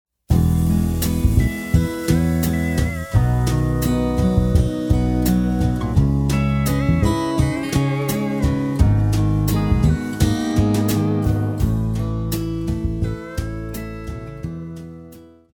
Classical
French Horn
Band
Etude,Course Material,Classical Music
Only backing